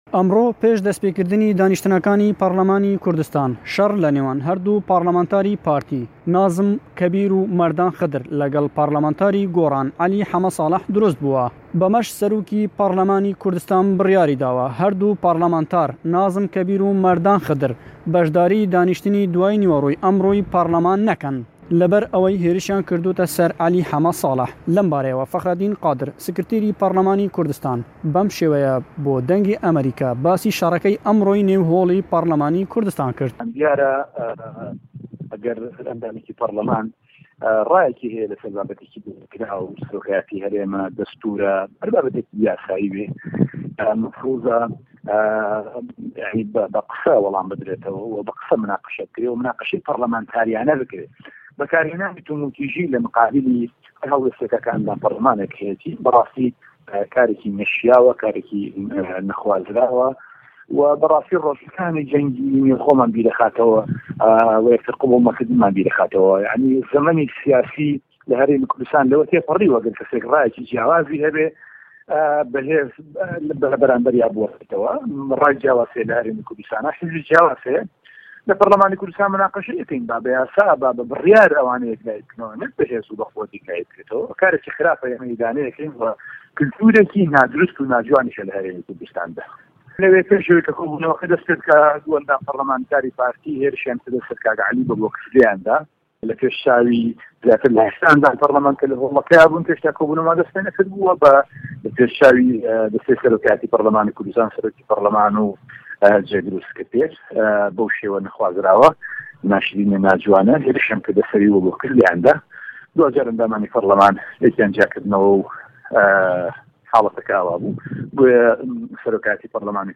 ڕاپـۆرتێـک سه‌باره‌ت به‌ شه‌ڕه‌که‌ی نێو په‌رله‌مانی هه‌رێمی کوردسـتانی عێراق